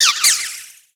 Cri de Bombydou dans Pokémon Soleil et Lune.